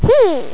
hmm.wav